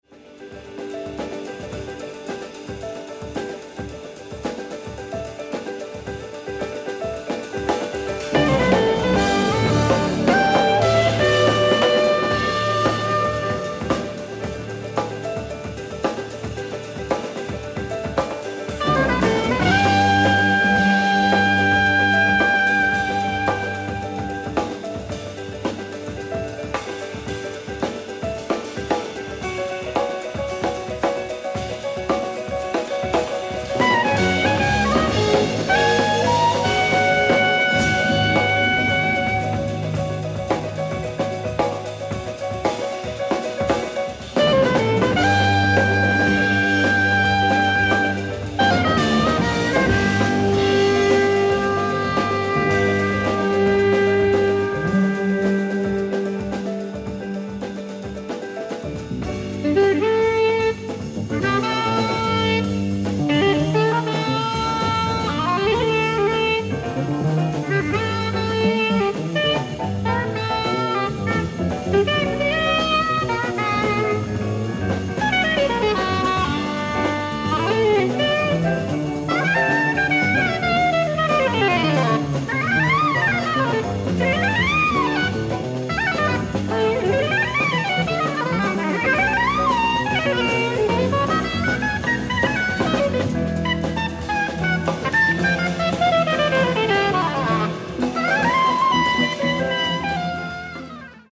ディスク１＆２：ライブ・アット・サンフランシスコ、カリフォルニア 10/14/1979
ディスク３：ライブ・アット・デュッセルドルフ、ドイツ 03/13/1979
※試聴用に実際より音質を落としています。